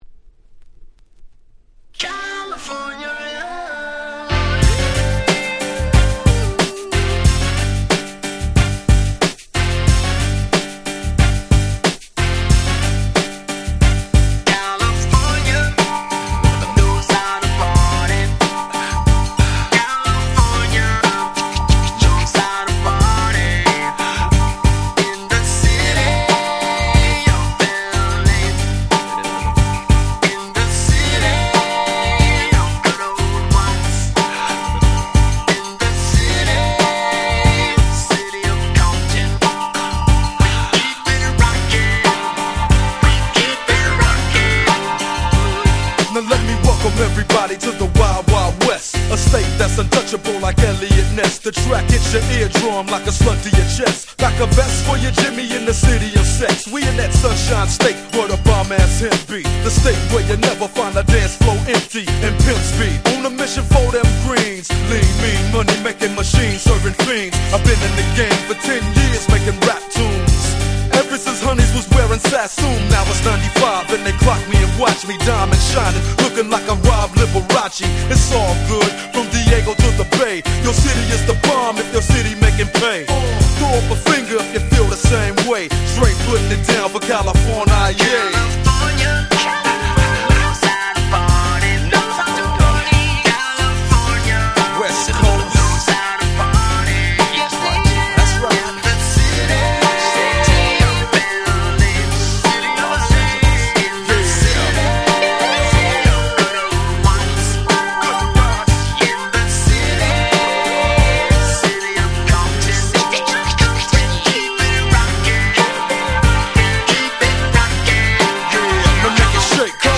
問答無用のWest Coast Hip Hop Classics !!